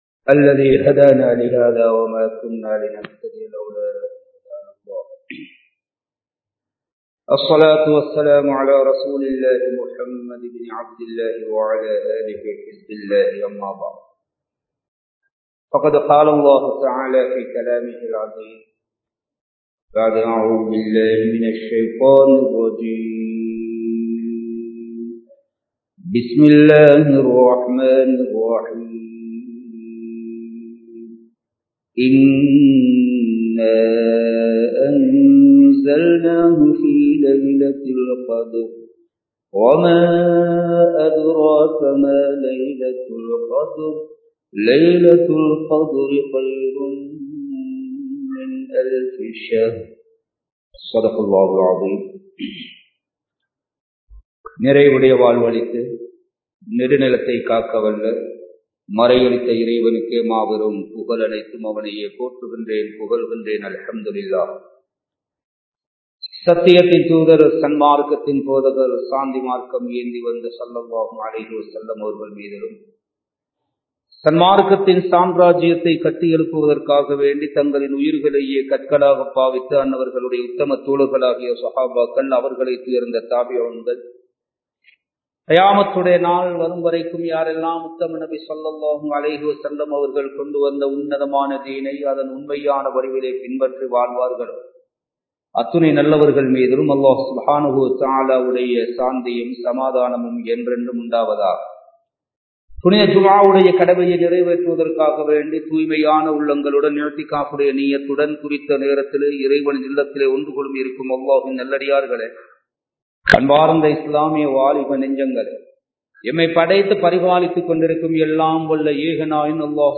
அல்லாஹ்வின் மகத்துவம் | Audio Bayans | All Ceylon Muslim Youth Community | Addalaichenai
Kandy, Kattukela Jumua Masjith